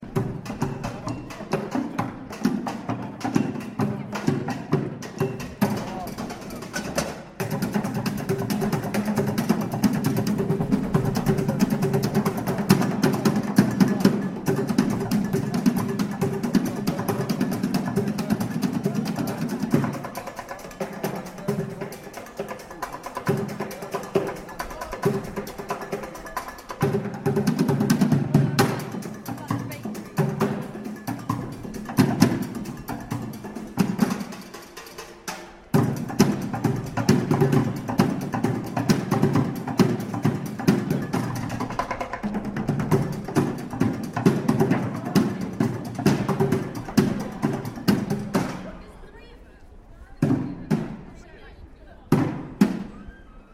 another percussion group from Victorian Fayre.